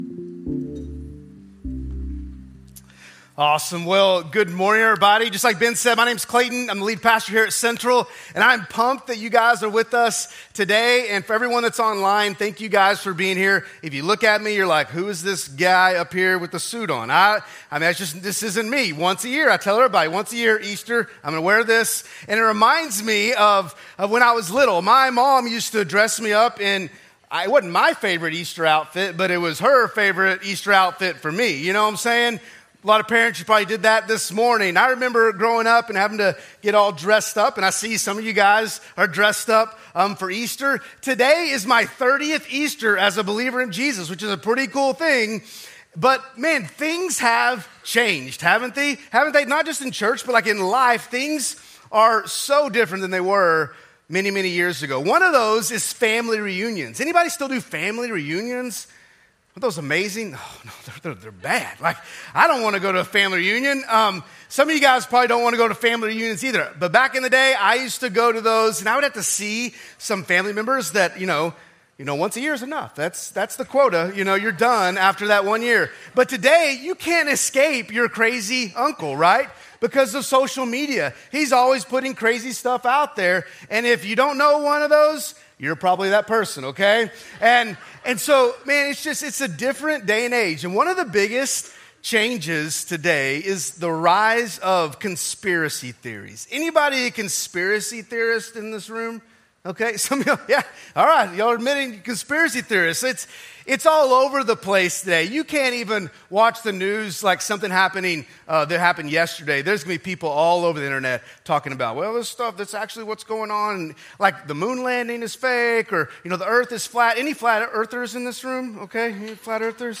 Our at home edition of Easter at Central.
Sermon